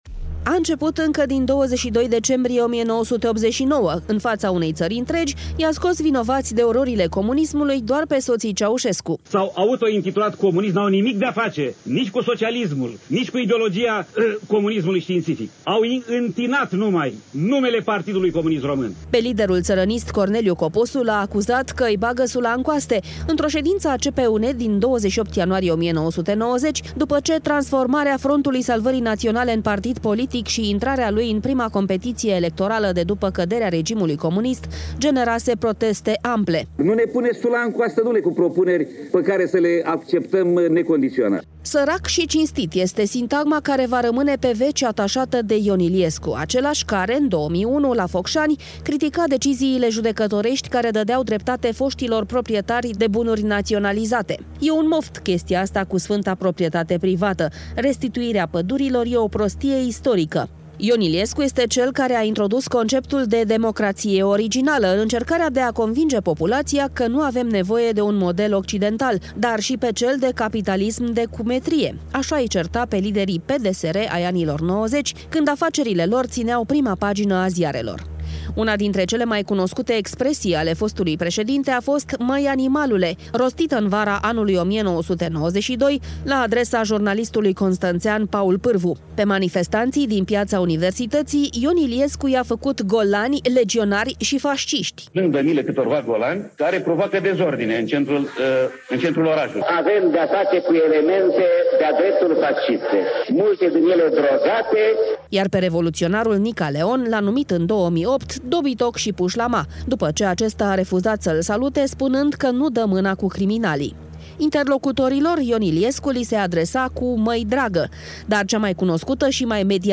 Cele mai cunoscute expresii rostite de Ion Iliescu